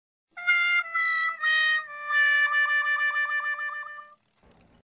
Tiếng Wha Wha Whaaa